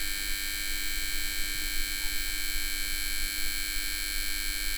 BuzzingSound.wav